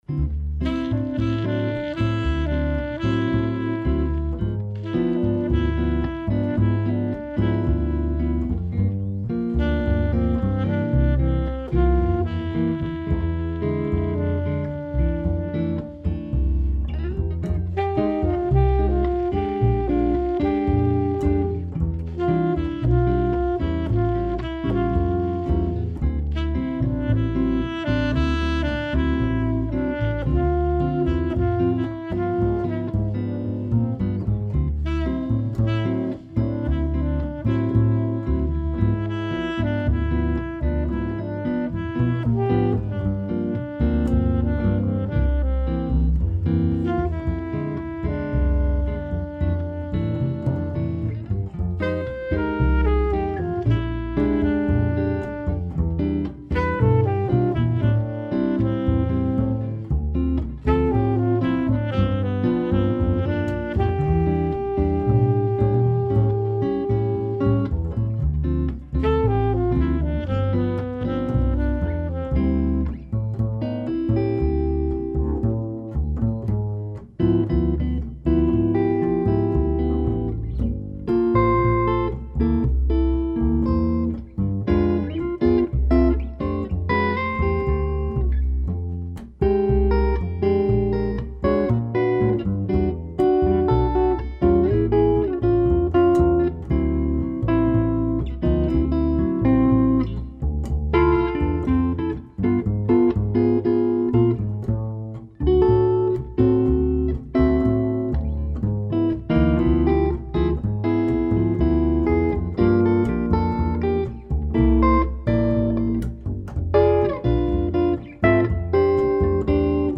Melodisk og swingende jazz.
jazzguitar, saxofon og kontrabas
• Jazzband